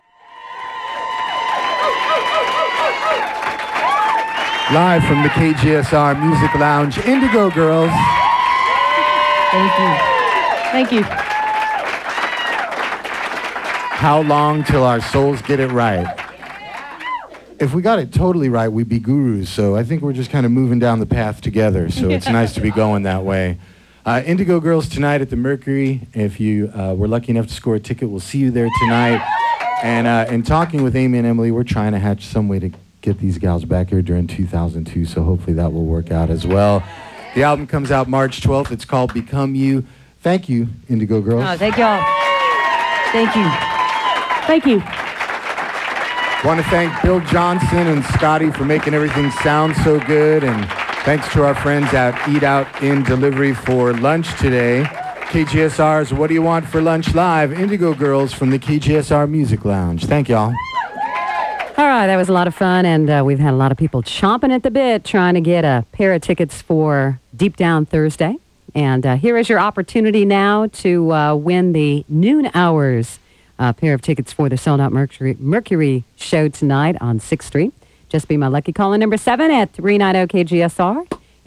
(acoustic duo show)
07. interview (1:21)